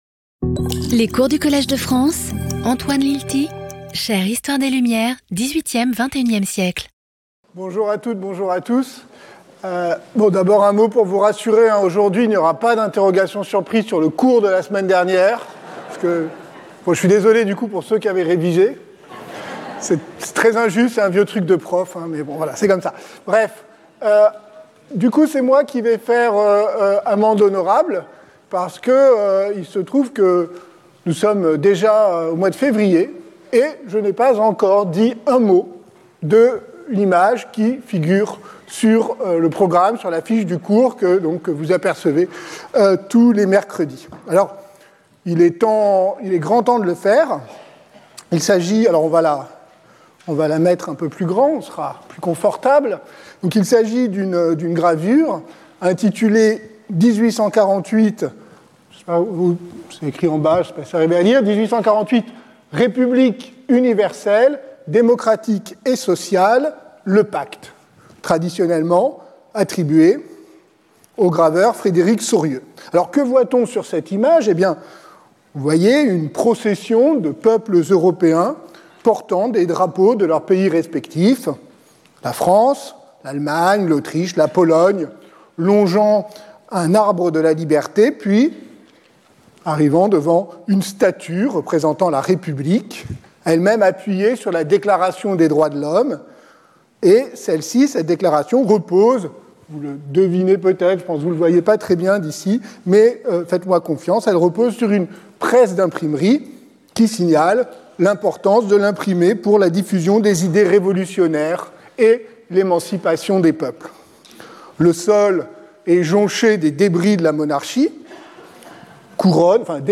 References to works cited in the lecture Maurice Agulhon, Marianne au combat.